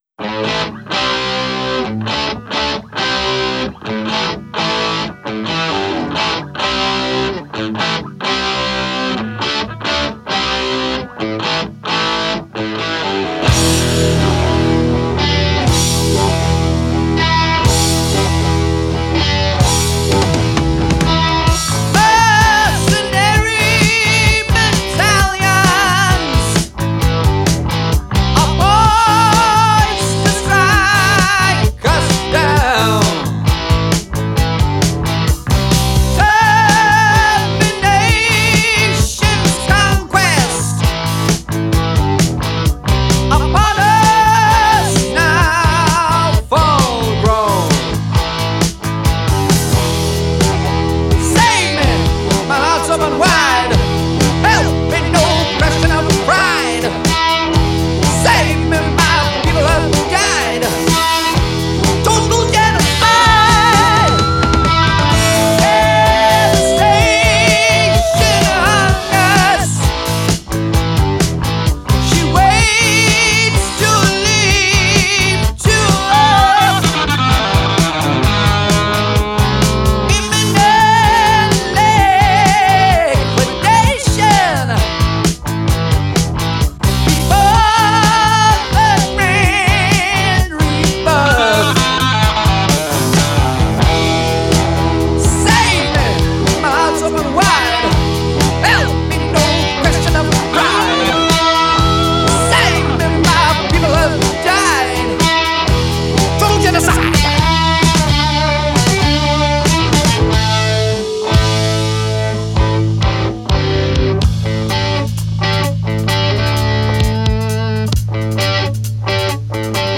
evil, slashing riffs, demonic guitar duels
screaming, theatrical vocals
thrashing